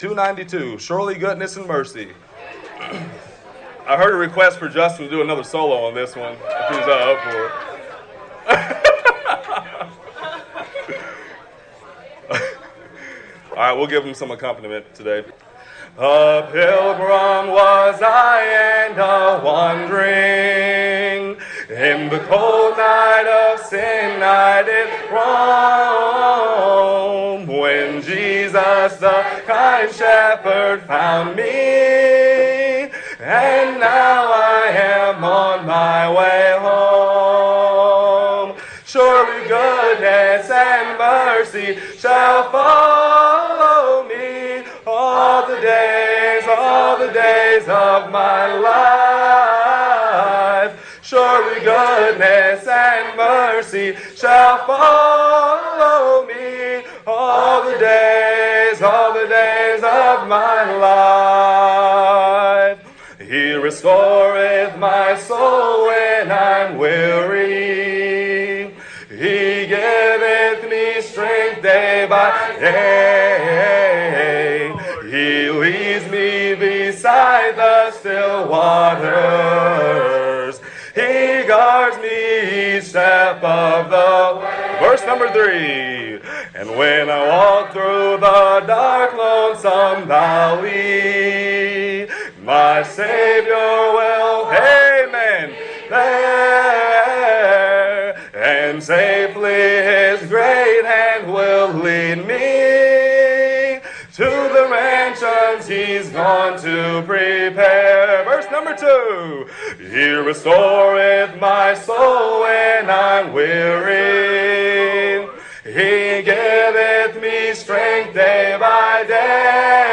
Camp Songs: